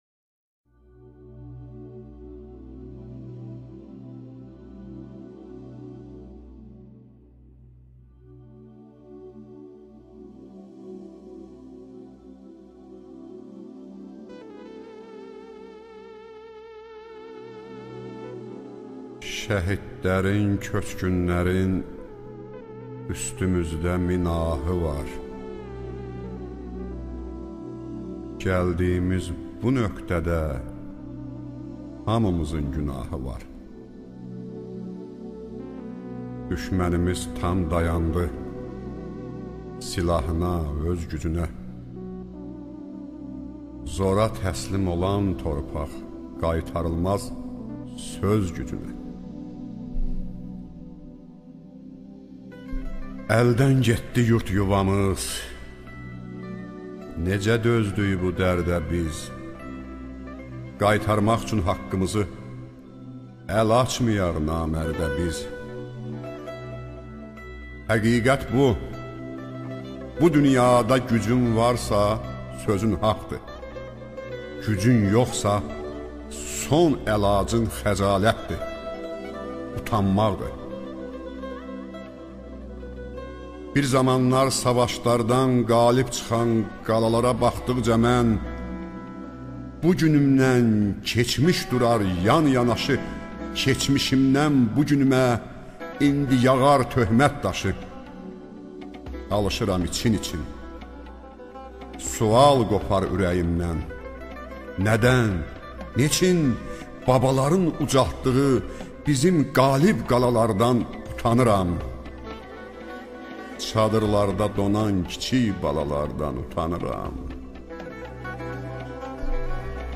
ŞEİRLƏR
AKTYORLARIN İFASINDA